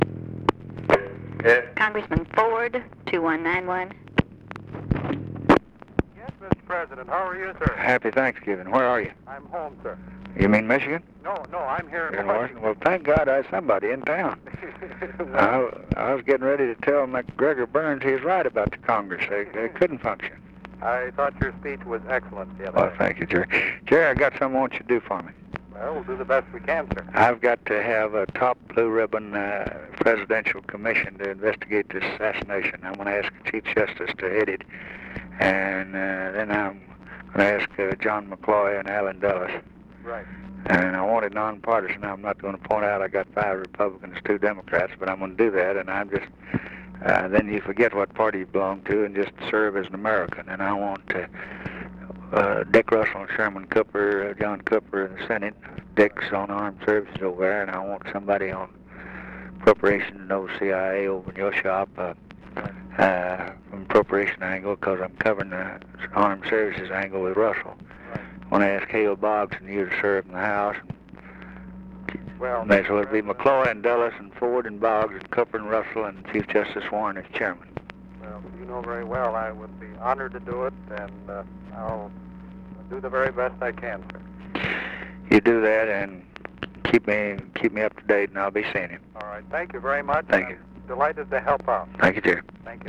Conversation with GERALD FORD, November 29, 1963
Secret White House Tapes